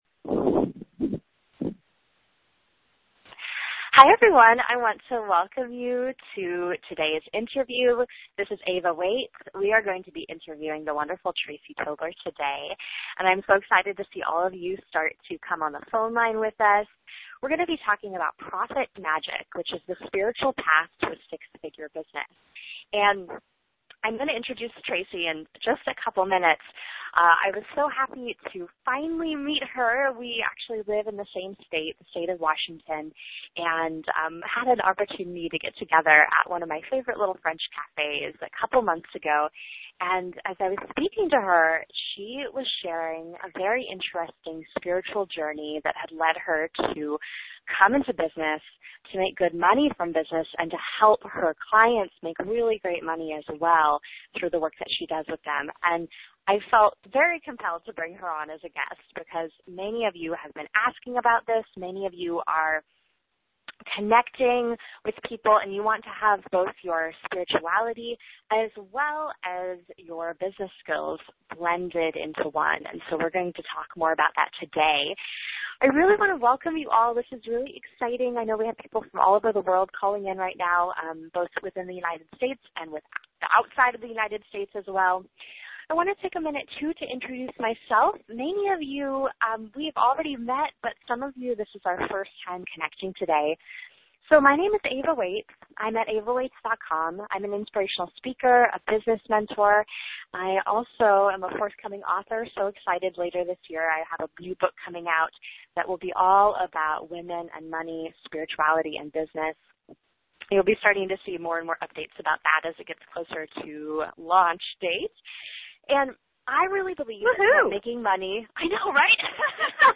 This interview first aired Thursday, January 22nd, 2015